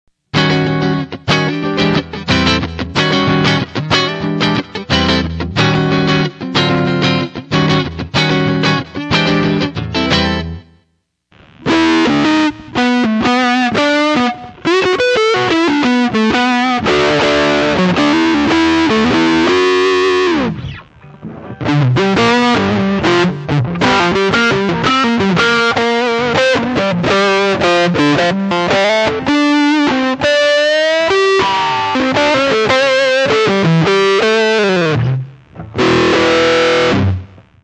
BazzFuss sample, low gain and high gain Starts with the gain set all the way down, then with gain full up, neck pickup(Fender-style single coil), and ending with gain full up, bridge humbucker.